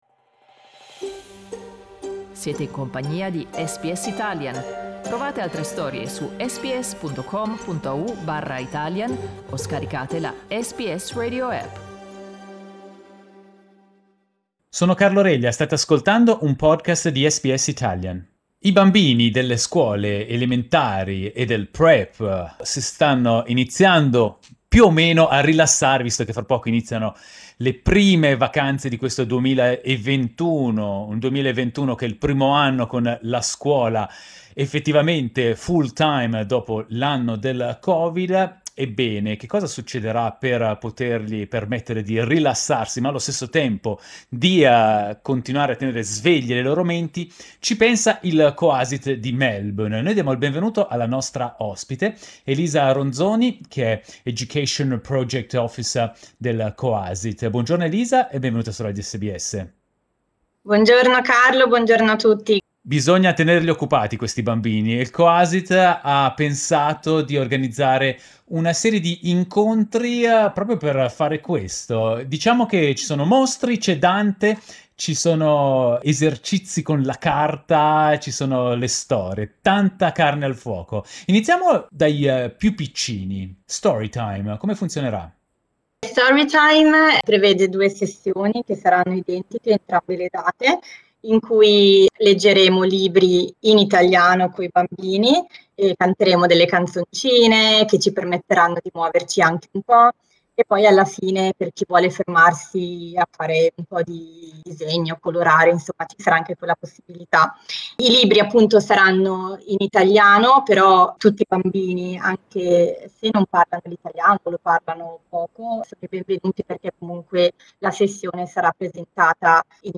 ha raccontato il progetto ai microfoni di Sbs Italian